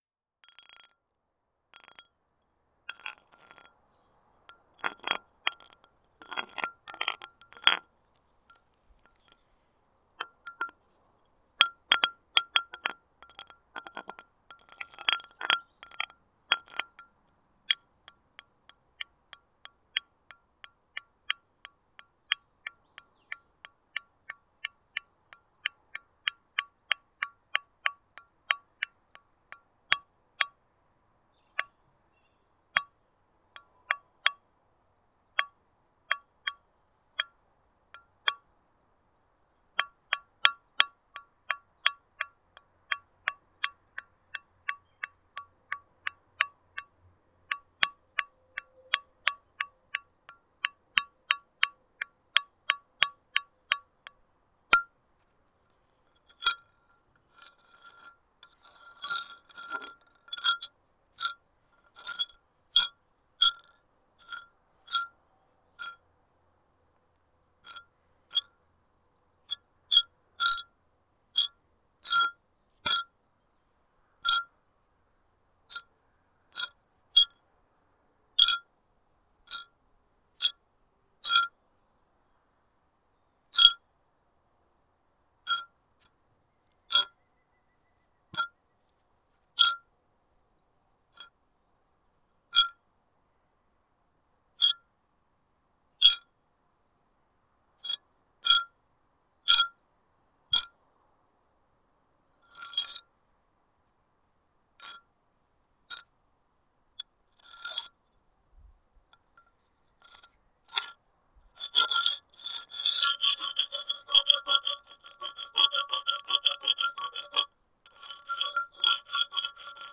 Pilar de ardósia sobre muro de granito junto a vinha em Boa Aldeia, Boa Aldeia a 22 Março 2016.
NODAR.00531 – Boa Aldeia, Farminhão e Torredeita: Pilar de ardósia sobre muro de granito junto a vinha em Boa Aldeia